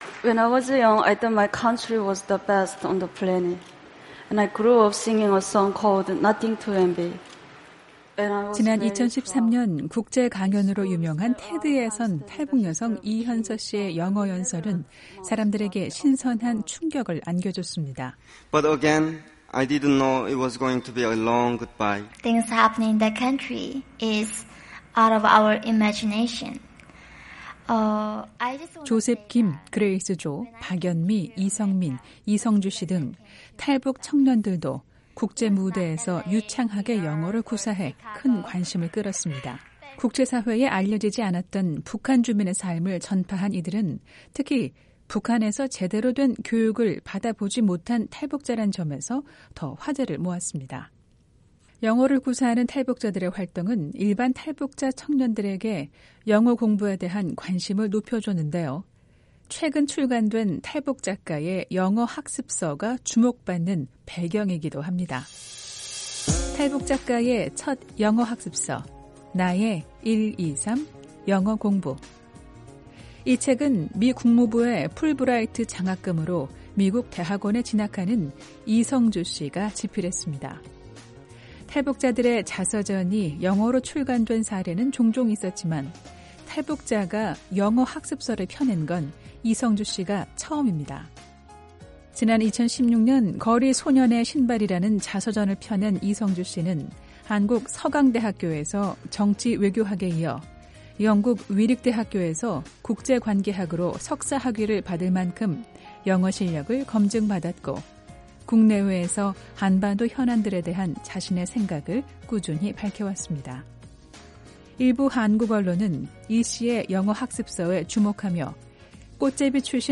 생생 라디오 매거진, 한 주 간 북한 관련 화제성 뉴스를 전해 드리는 ‘뉴스 풍경’ 입니다. 올 여름부터 미국 대학원에서 박사 과정을 밟는 30대 탈북 남성이 최근 영어공부 비법을 소개하는 책을 펴냈습니다.